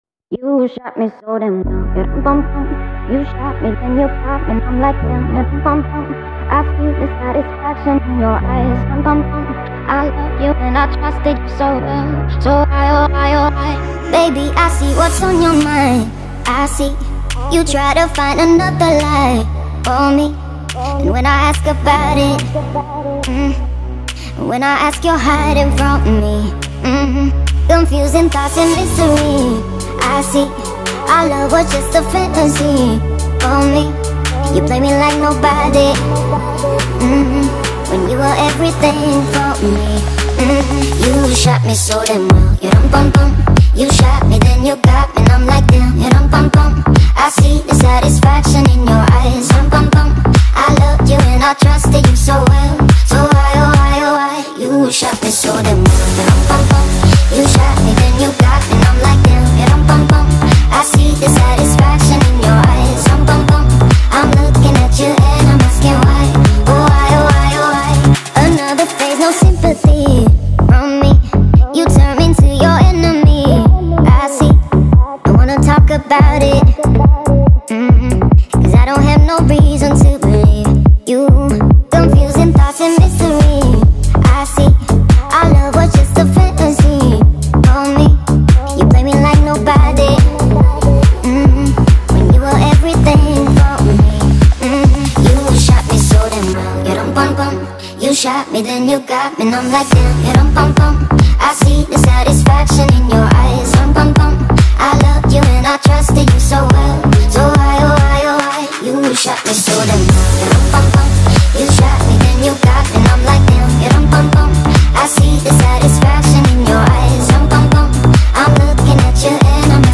نسخه افزایش سرعت صدای بچه